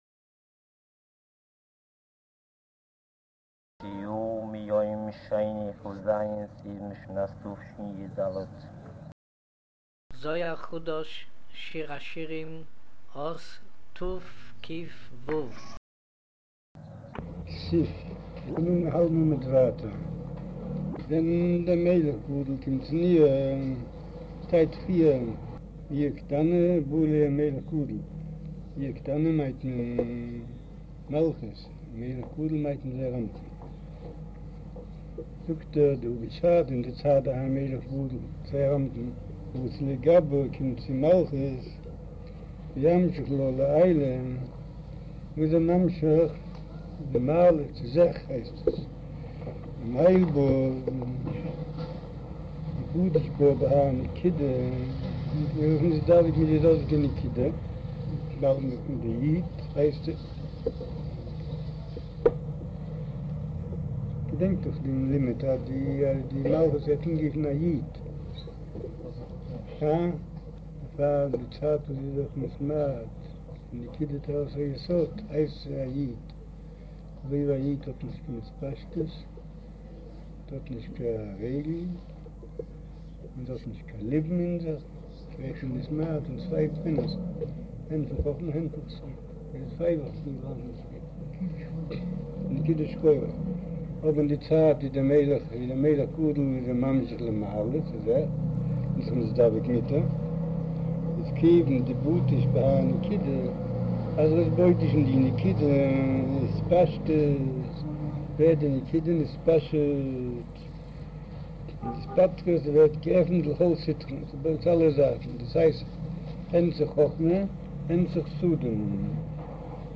אודיו - שיעור